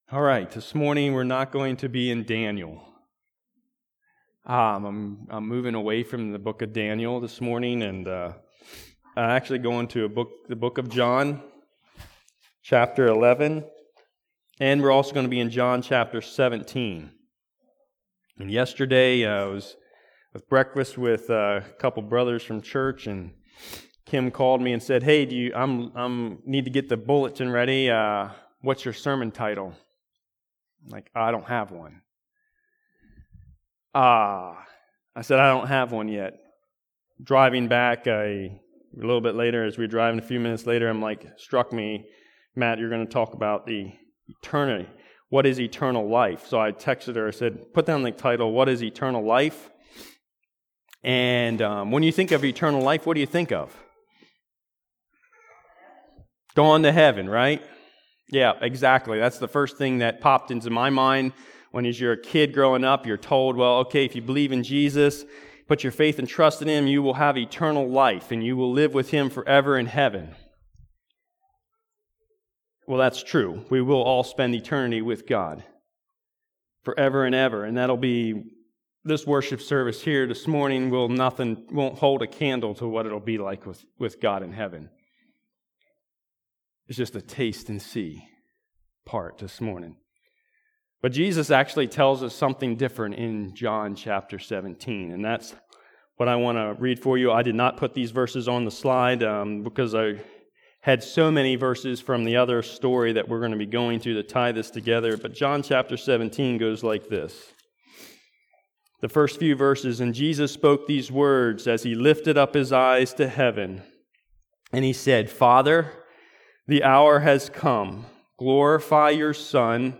Maranatha Fellowship's Sunday Morning sermon recordings.
Sunday Sermons